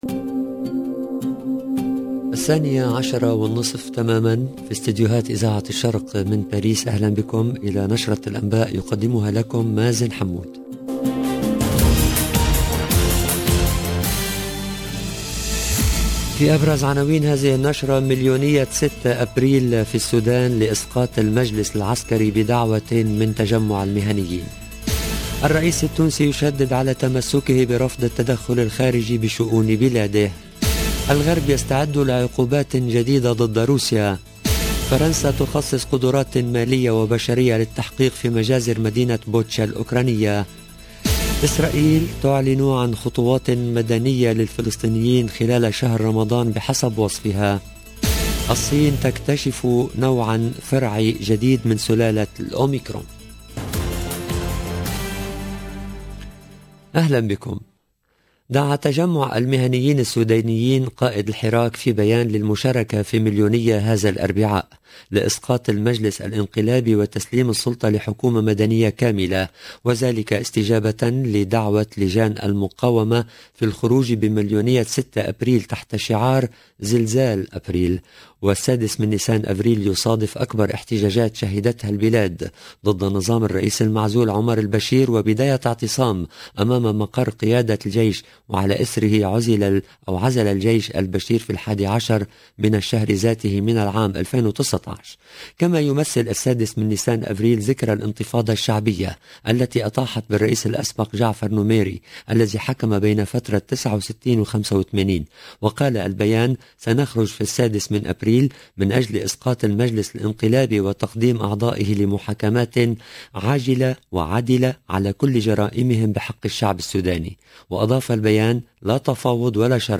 LE JOURNAL DE MIDI 30 EN LANGUE ARABE DU 6/04/22